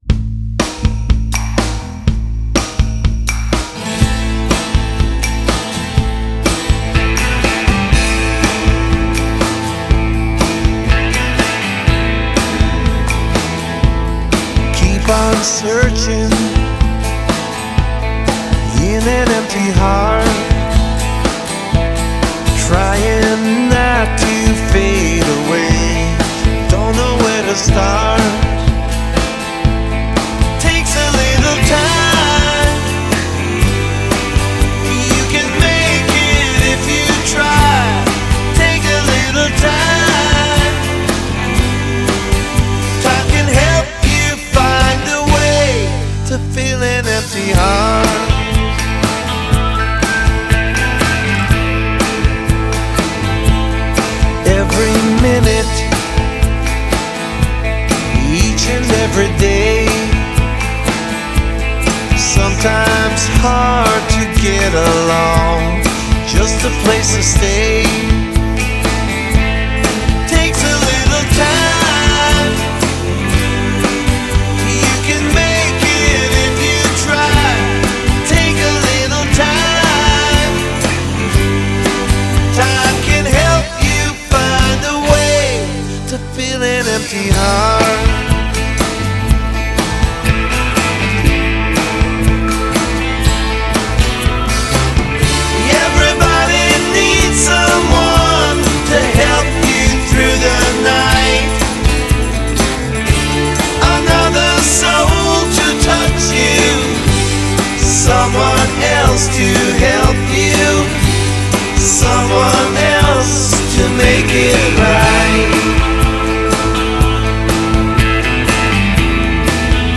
and oh-so-new wave guitars.